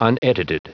Prononciation du mot unedited en anglais (fichier audio)
Prononciation du mot : unedited